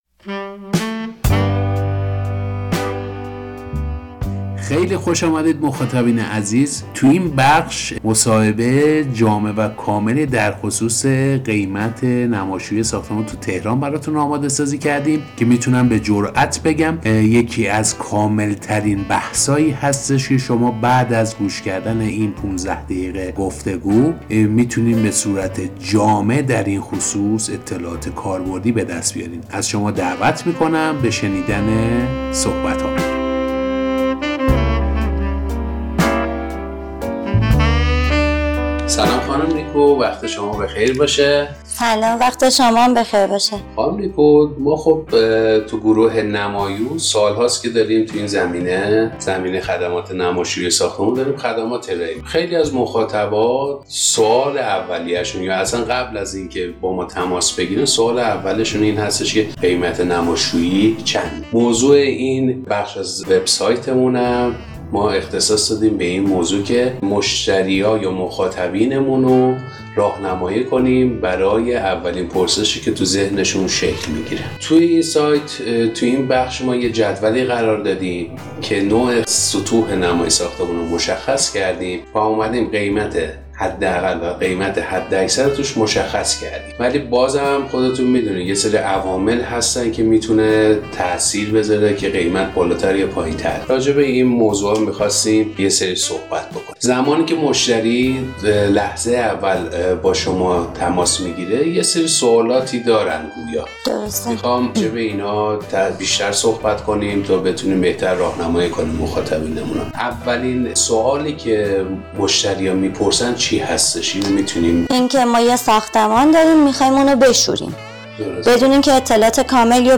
قیمت نماشویی ساختمان در تهران گفتگو جامع و کارشناسی.( برای شنیدن فایل صوتی کلیک نمایید ) ► در مقاله‌ای جداگانه، به تاثیر موقعیت جغرافیایی بر هزینه شستشوی نما در تهران پرداختیم و دلایل این تاثیر را مورد بحث قرار دادیم.